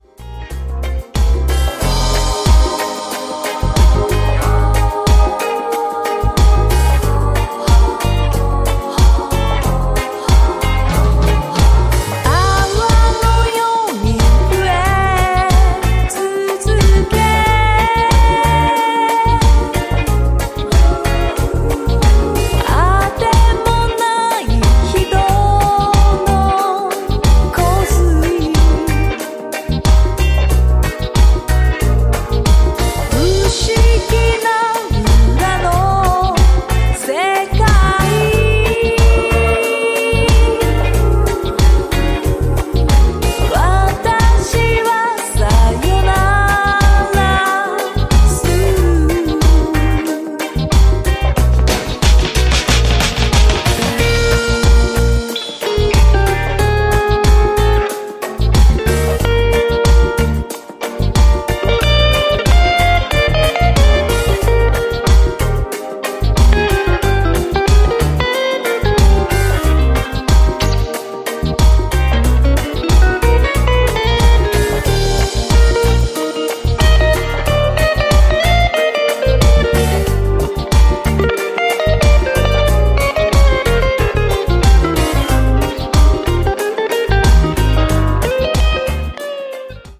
# CITY POP / AOR
とにかくメロウでソウルフルなカヴァー！